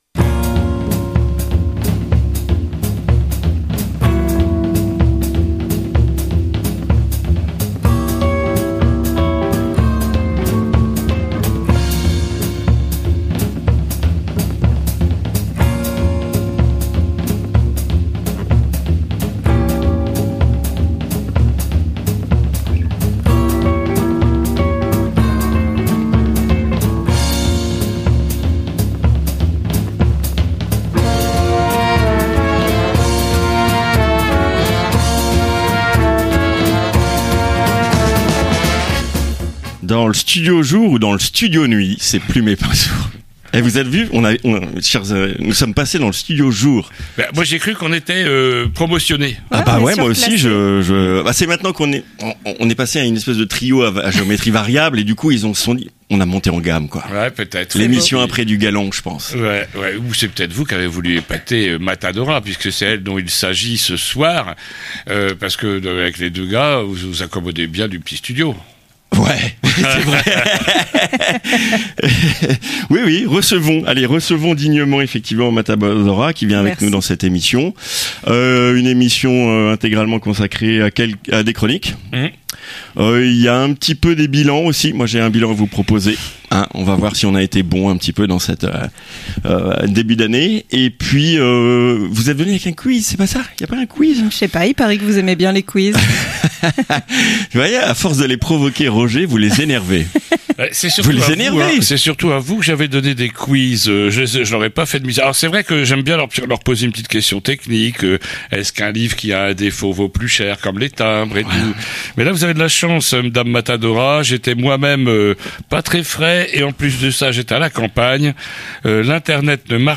Cette émission a été sauvé par la crème de la crème des animateurs de la Radio